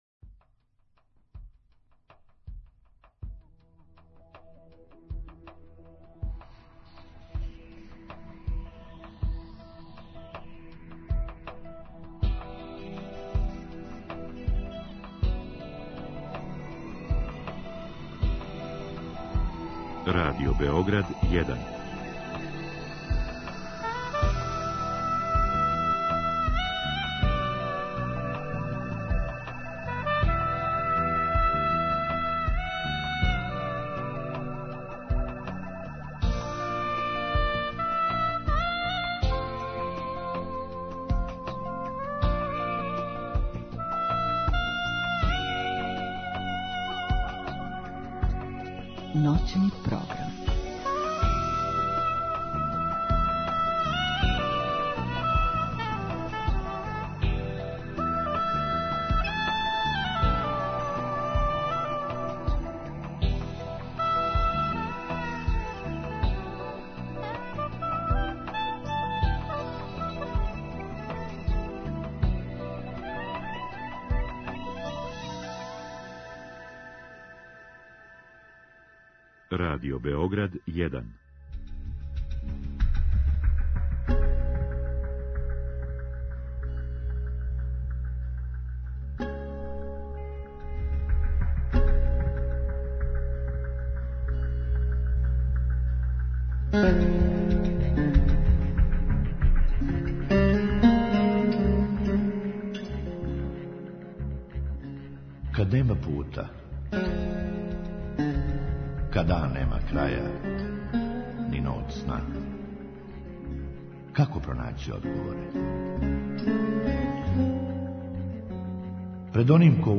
У другом сату слушаоци у директном програму могу поставити питање нашој гошћи.